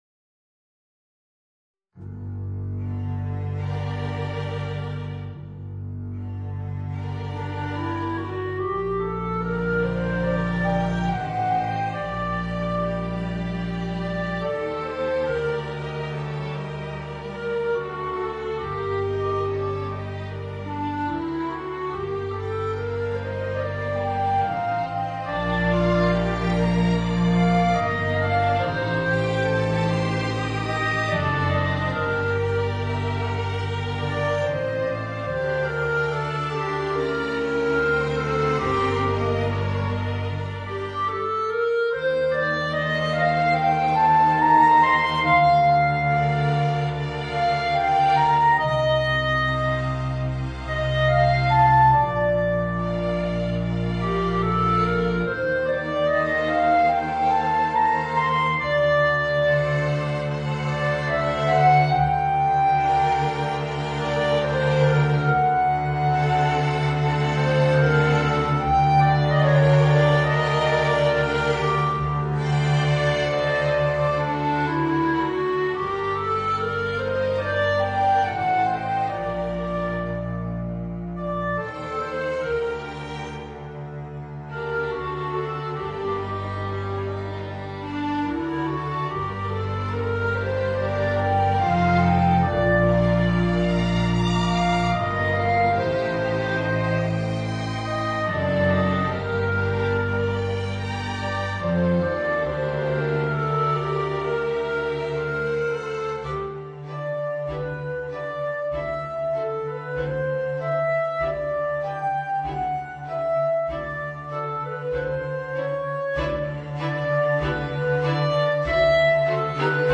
Voicing: Viola and String Quintet